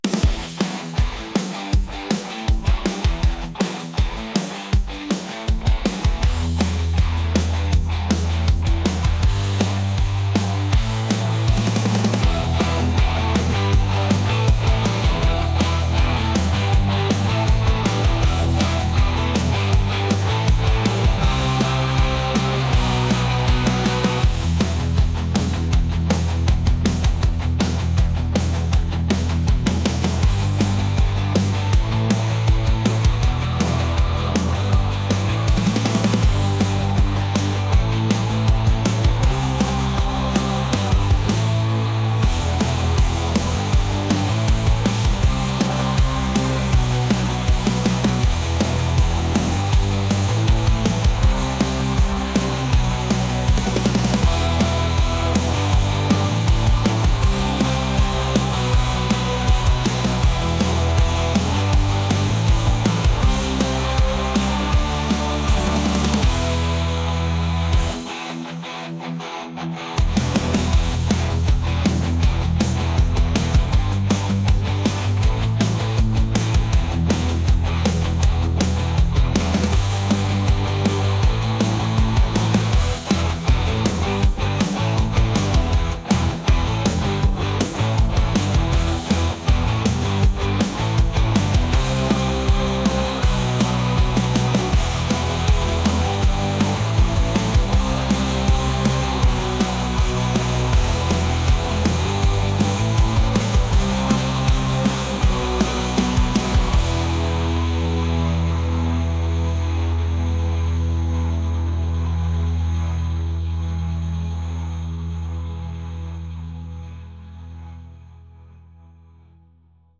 rock | punk | indie